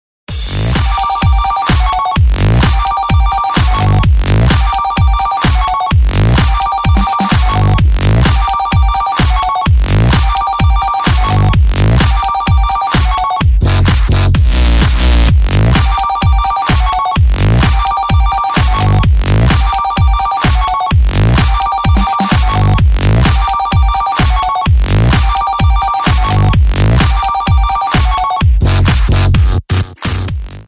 • Качество: 128, Stereo
громкие
dance
Electronic
EDM
без слов
Big Room
electro house
Стиль: electro house, big room